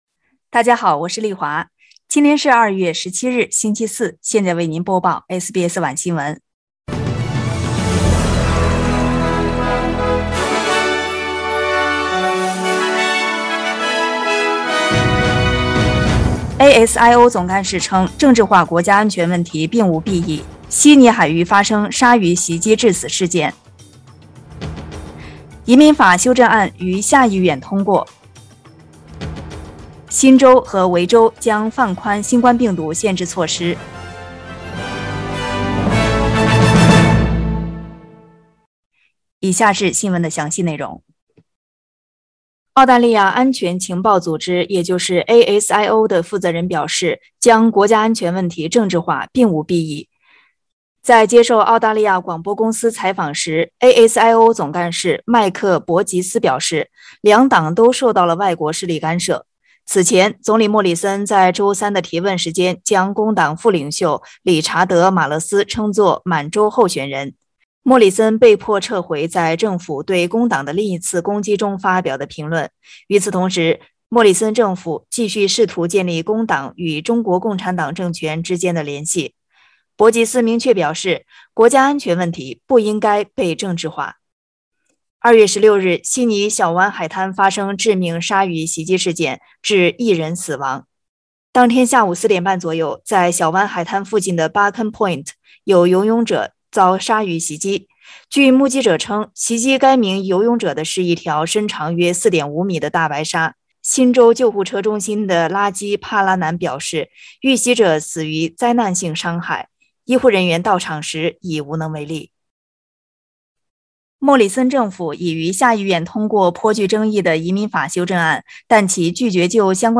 SBS晚新闻（2022年2月17日）
Source: SBS Mandarin evening news Source: Getty Images